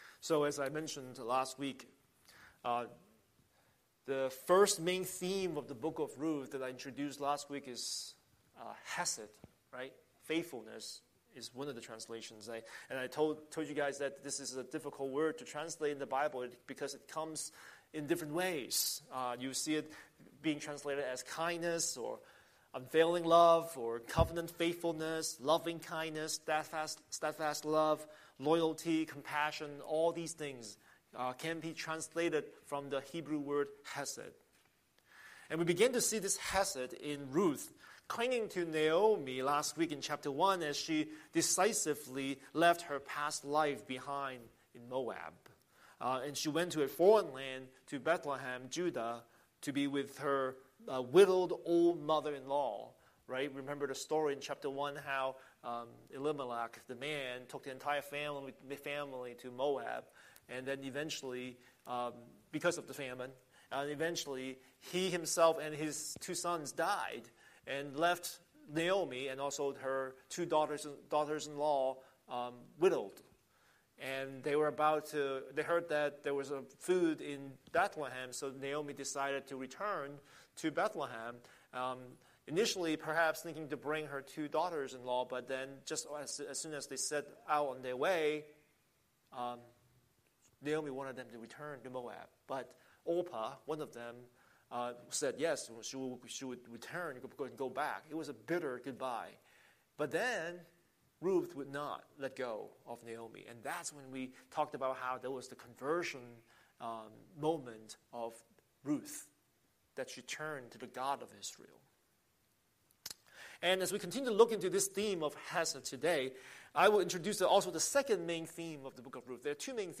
Scripture: Ruth 2:1-23 Series: Sunday Sermon